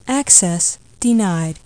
AccessDeny.mp3